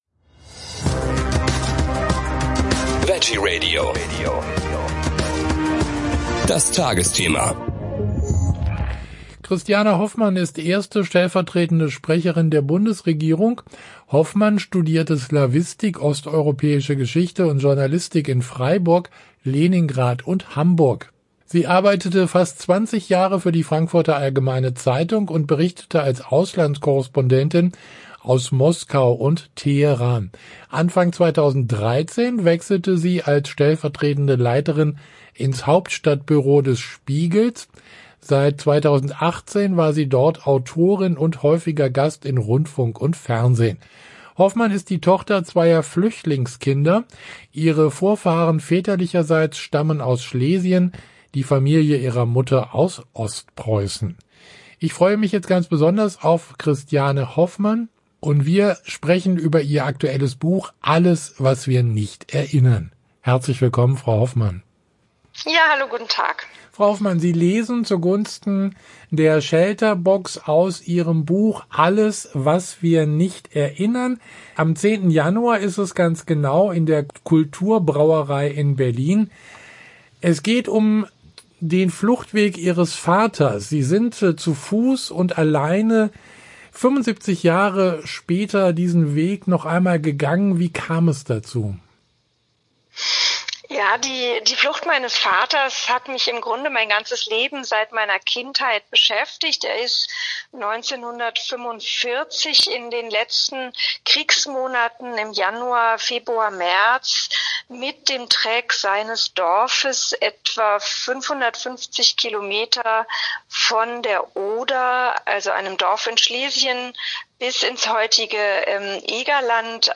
🎧 Christiane Hoffmann liest zu Gunsten von ShelterBox
Charity-Lesung